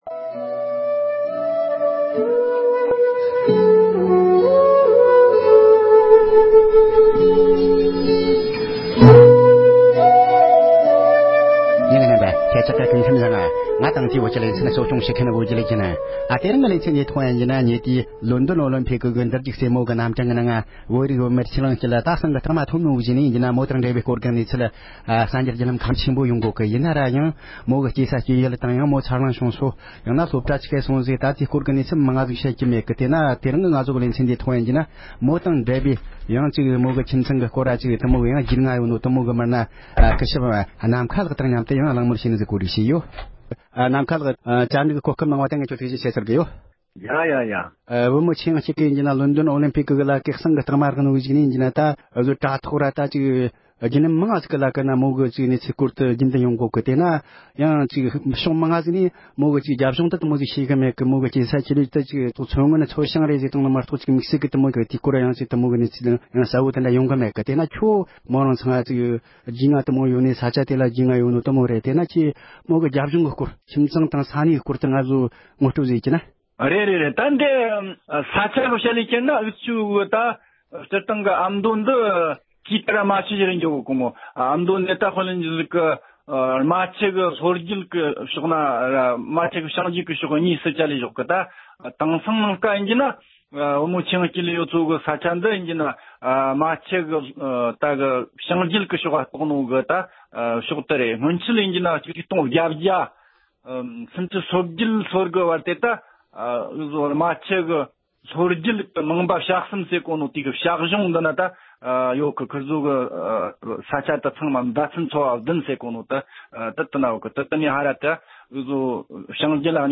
ཨོ་ལེམ་པིག་གོམ་བགྲོས་ཀྱི་འགྲན་བསྡུར་ནང་ཟངས་ཀྱི་གཟེངས་རྟགས་ཐོབ་མཁན་ཆོས་དབྱིངས་སྐྱིད་ཚར་ལོངས་བྱེད་ས་སྐོར་གླེང་མོལ།